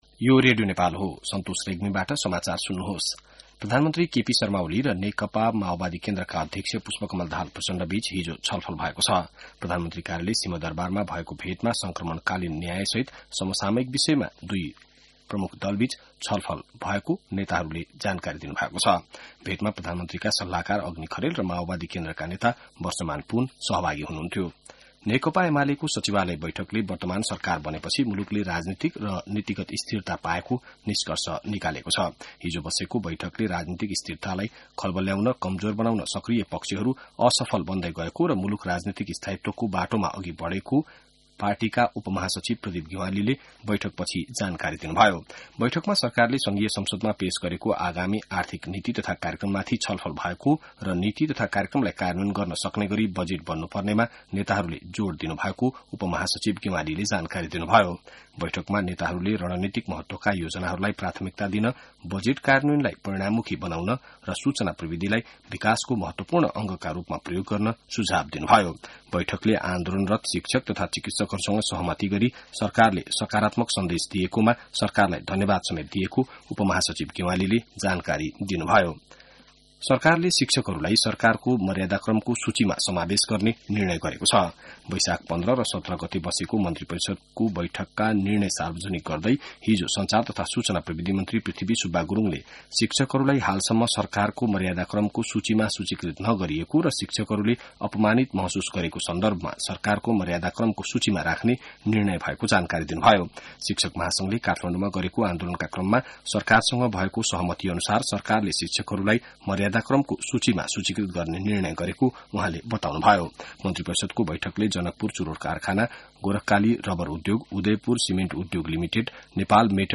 बिहान ६ बजेको नेपाली समाचार : २२ वैशाख , २०८२